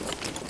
tac_gear_31.ogg